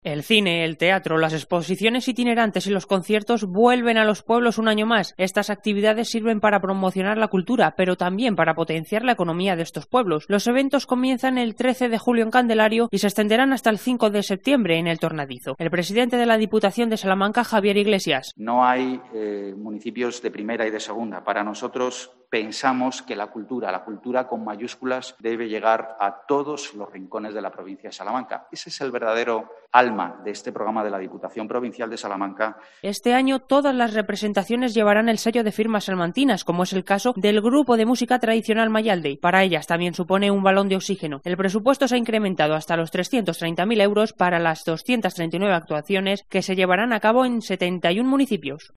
Javier Iglesias, presidente de la Diputación: "No hay pueblos pequeños para la cultura"